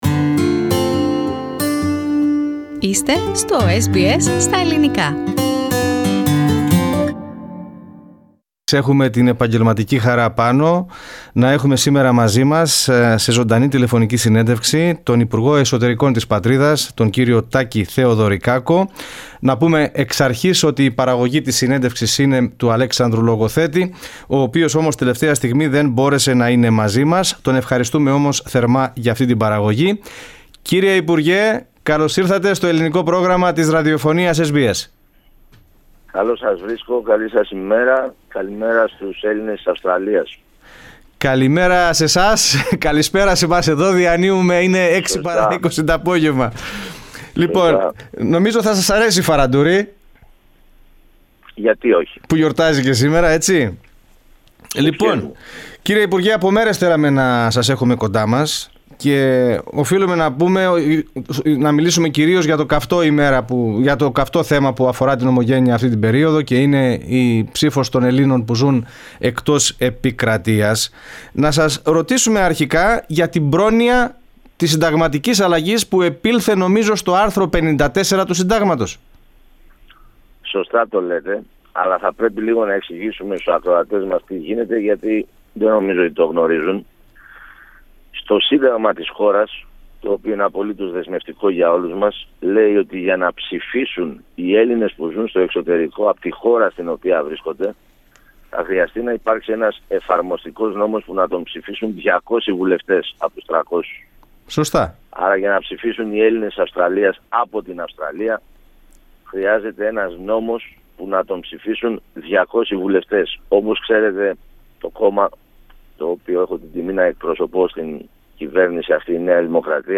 Στην κριτική που ασκείται από φορείς της ομογένειας για την λύση που δρομολογείται για την ψήφο των Ελλήνων υπηκόων εκτός ελληνικής επικράτειας αναφέρθηκε μεταξύ άλλων σε συνέντευξή του στο Ελληνικό Πρόγραμμα της Δημόσιας Ραδιοφωνίας SBS, ο υπουργός Εσωτερικών της Ελλάδας, Τάκης (Παναγιώτης) Θεοδωρικάκος.